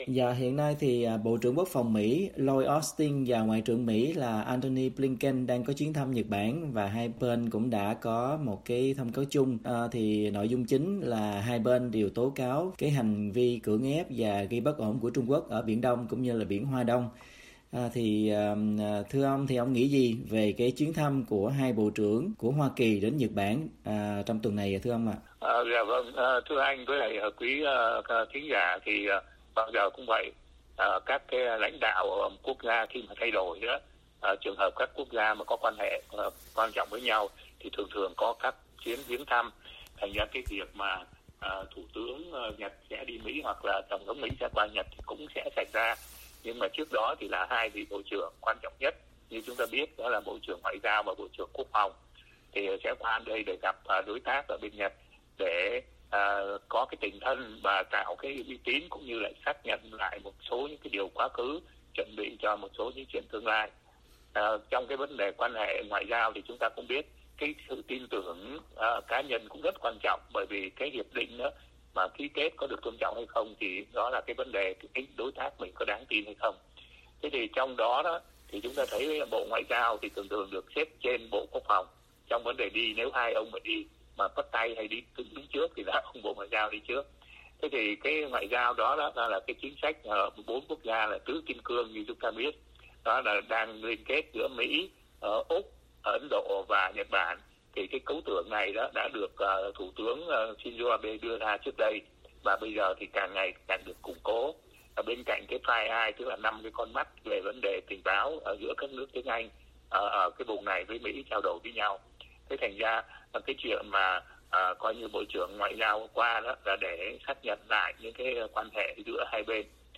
VOA phỏng vấn Nhà nghiên cứu